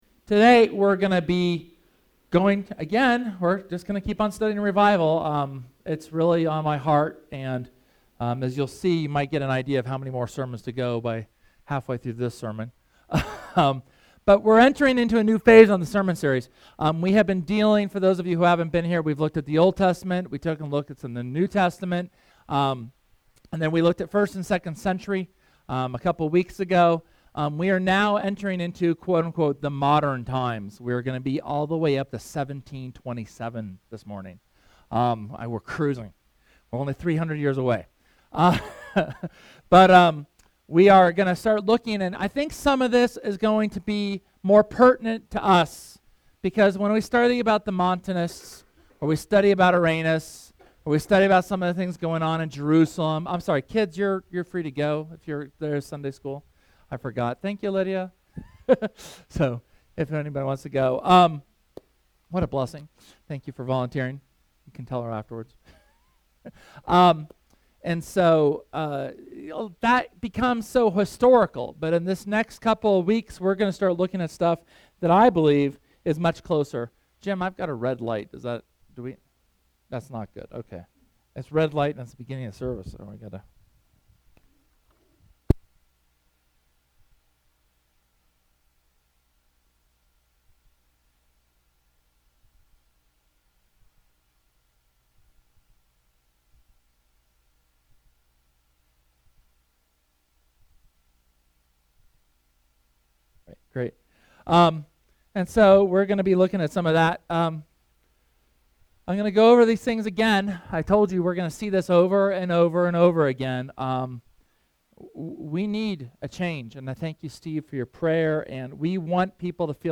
SERMON: Revival (6)